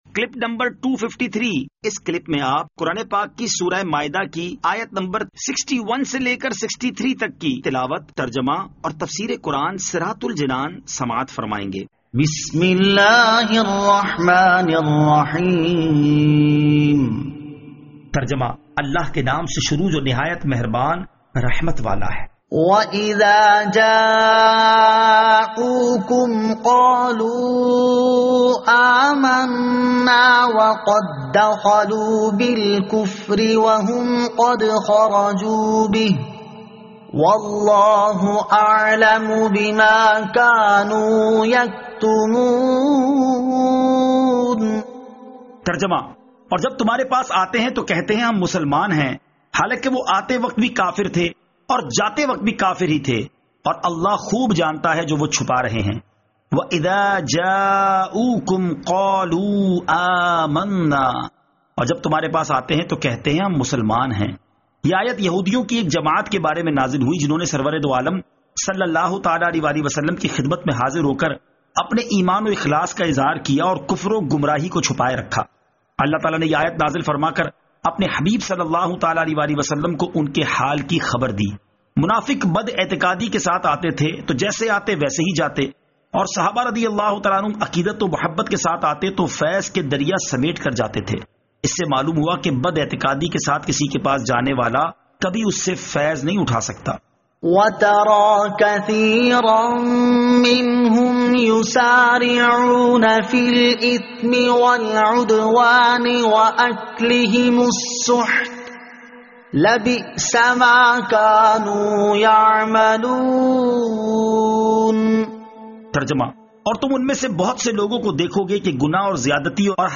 Surah Al-Maidah Ayat 61 To 63 Tilawat , Tarjama , Tafseer